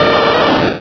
Cri de Carabaffe dans Pokémon Rubis et Saphir.